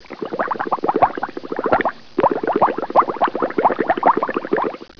Folder: water
bubble.wav